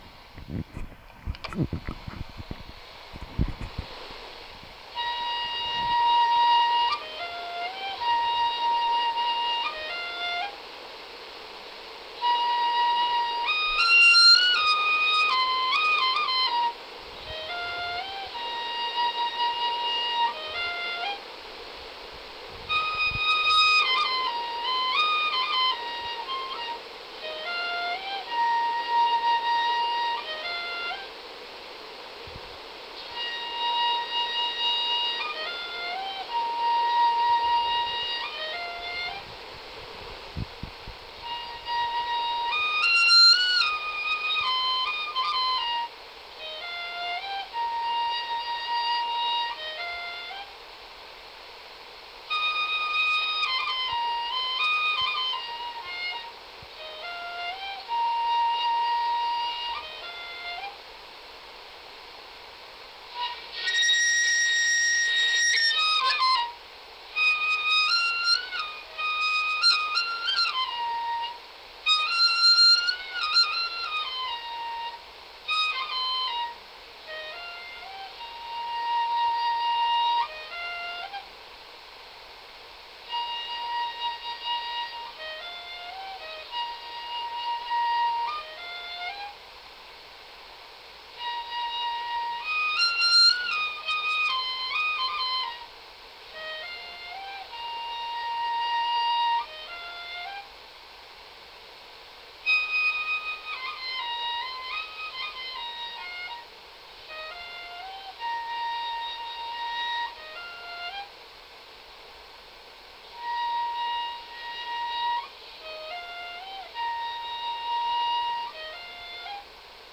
笛のメロディ  平元大太鼓　動画